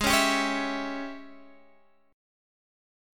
G#M7sus4#5 chord